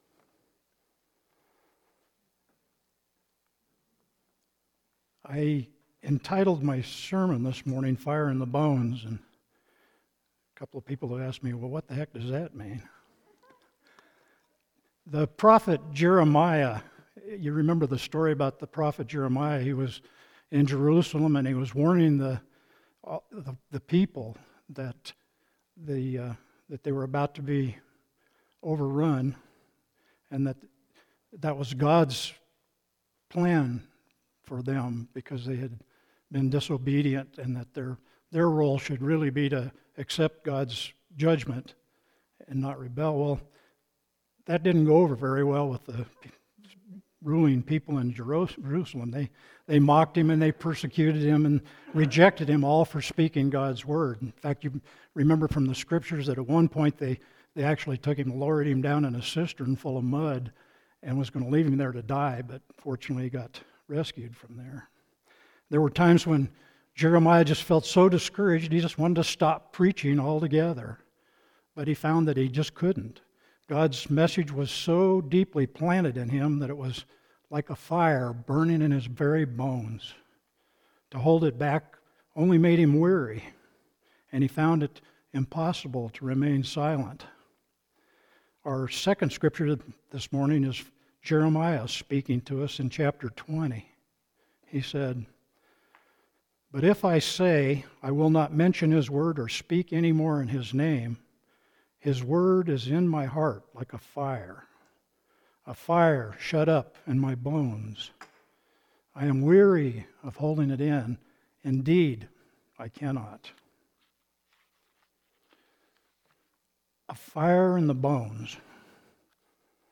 Sermon – October 12, 2025 – “Fire in the Bones”